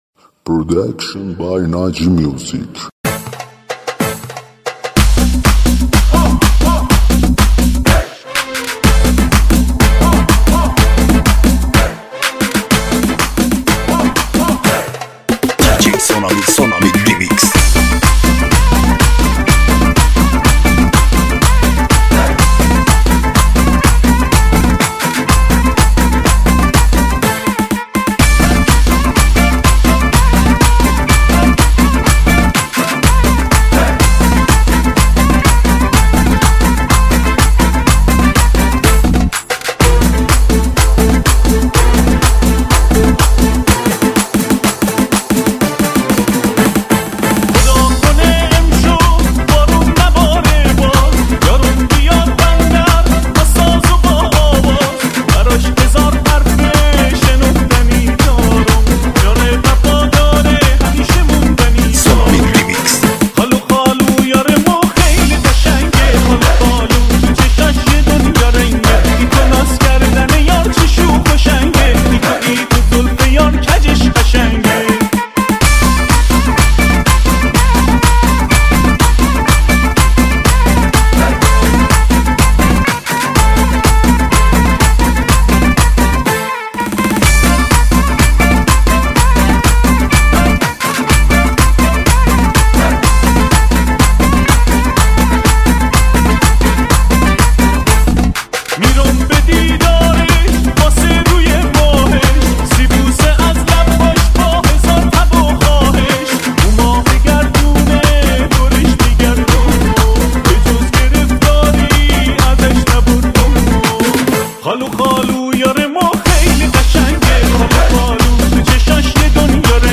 آهنگ شاد بندری
ریمیکس شاد عروسی
ریمیکس شاد نوستالژیک دهه 60